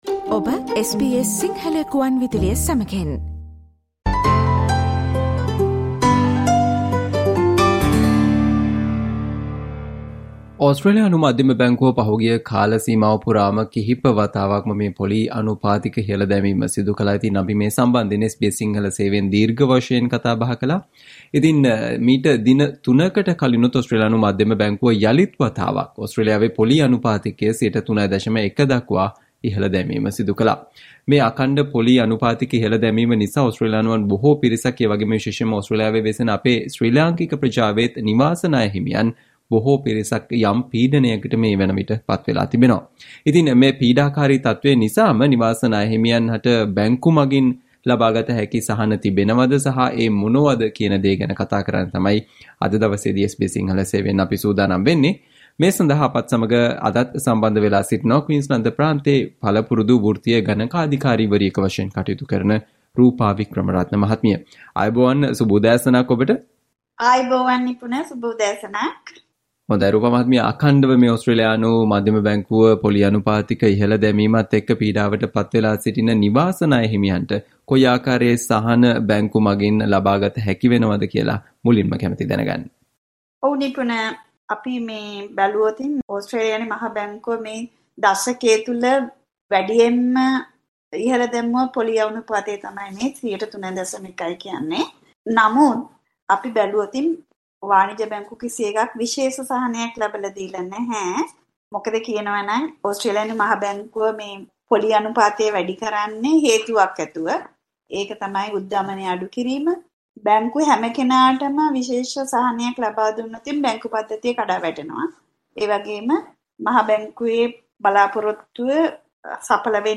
SBS Sinhala Discussion on What are the things you can do about your Home loans and concessions you can get from Banks, with the RBA Rate Rise?